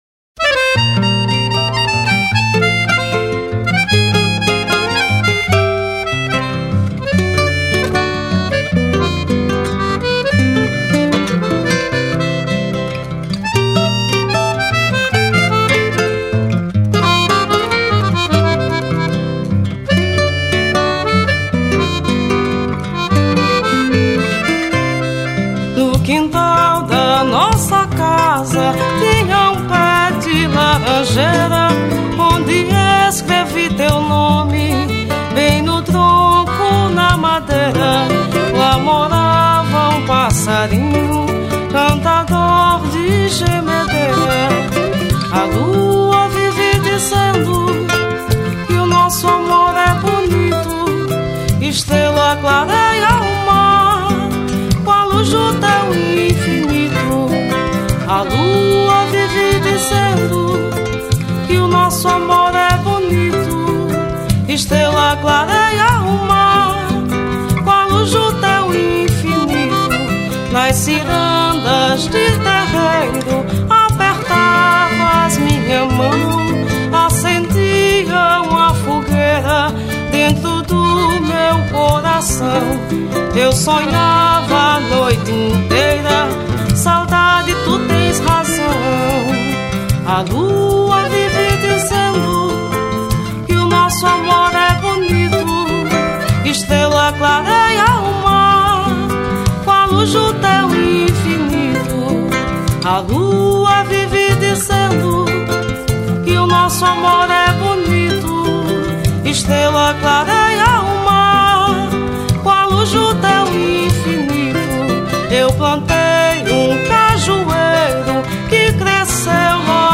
383   04:20:00   Faixa:     Ciranda
Voz
Violao Acústico 6
Violao 7
Acoordeon
Percussão